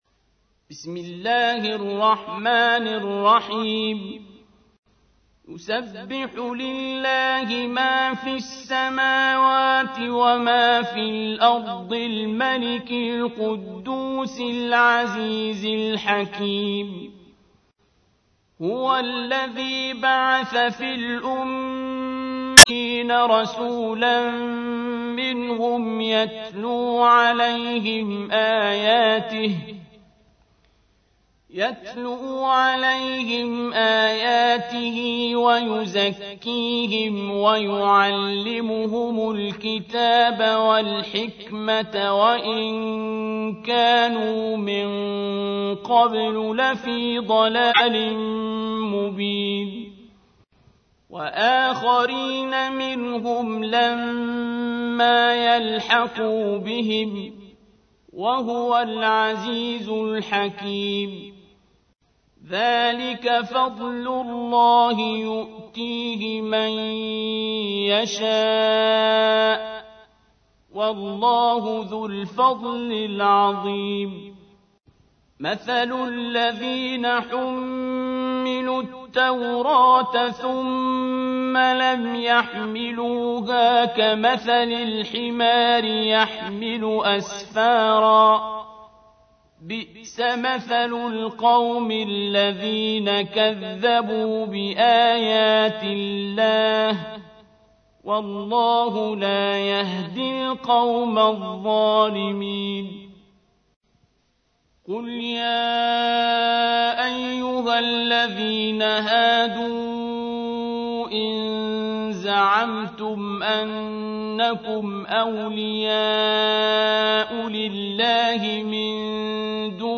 تحميل : 62. سورة الجمعة / القارئ عبد الباسط عبد الصمد / القرآن الكريم / موقع يا حسين